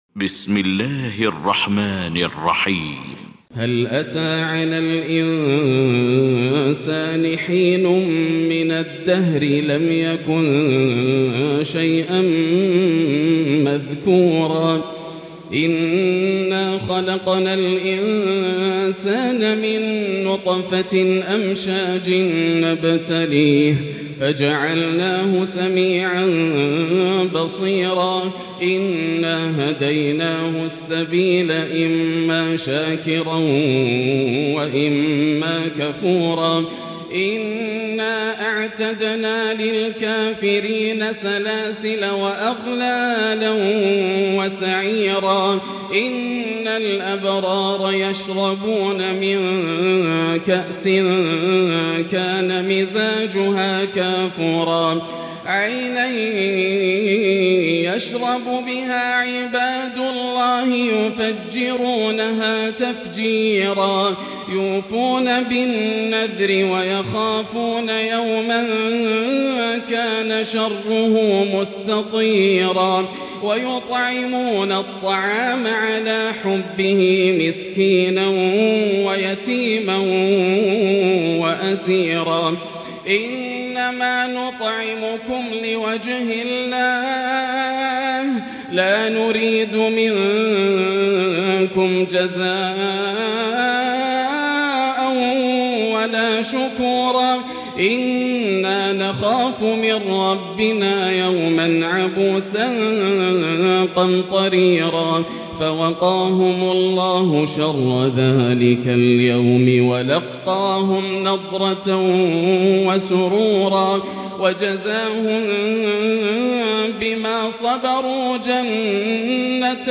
المكان: المسجد الحرام الشيخ: فضيلة الشيخ ياسر الدوسري فضيلة الشيخ ياسر الدوسري الإنسان